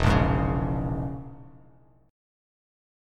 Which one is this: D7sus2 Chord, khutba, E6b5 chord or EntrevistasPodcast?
E6b5 chord